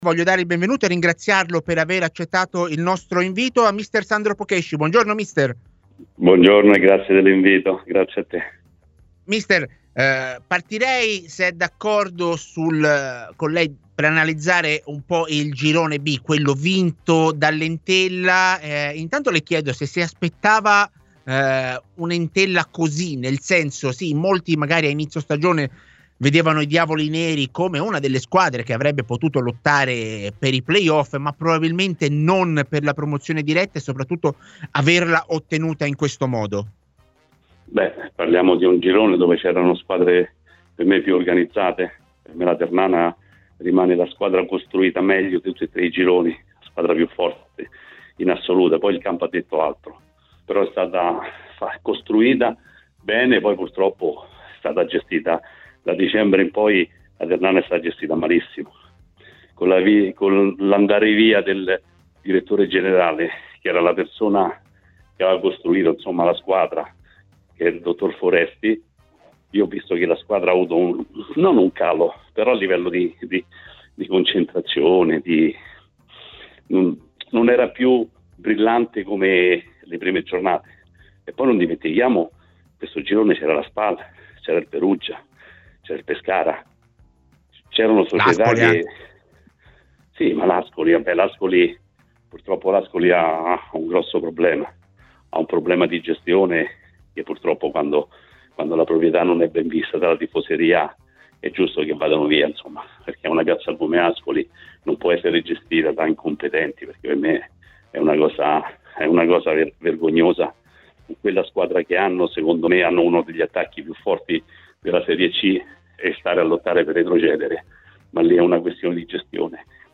è intervenuto ai microfoni di TMW Radio nel corso della trasmissione A Tutta C .